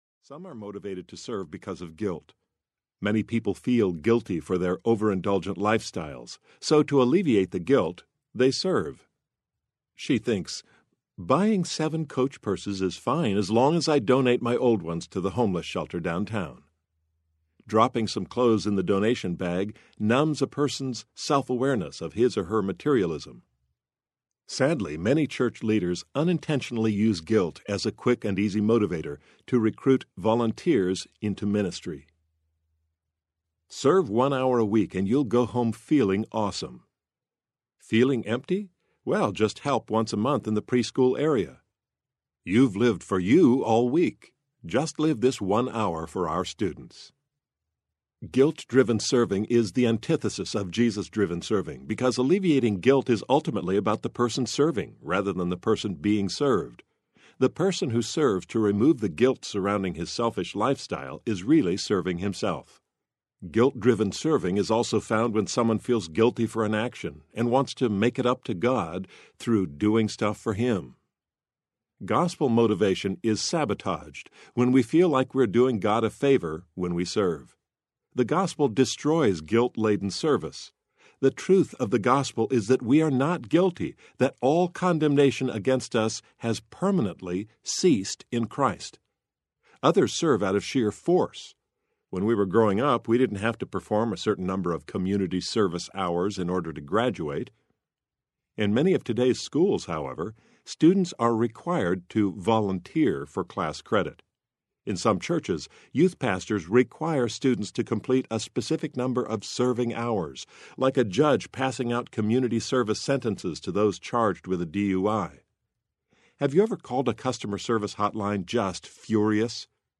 Creature of the Word Audiobook
Narrator
7.9 Hrs. – Unabridged